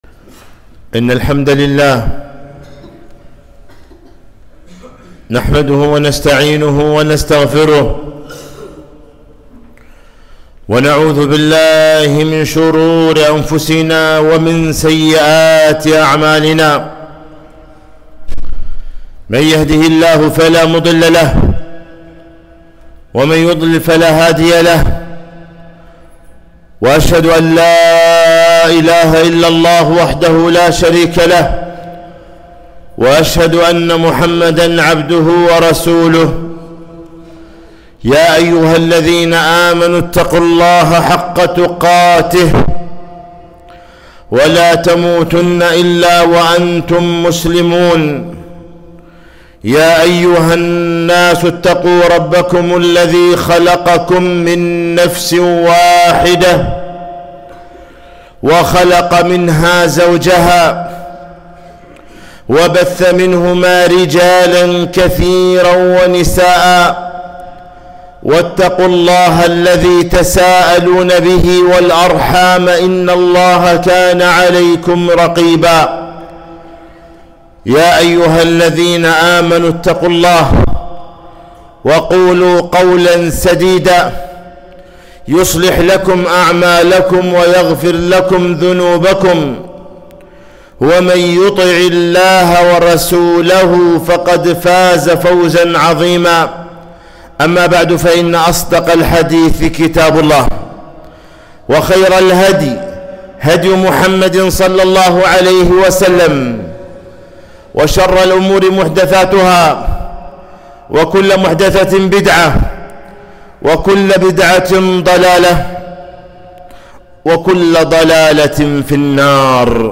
خطبة - اعبروها ولا تعمروها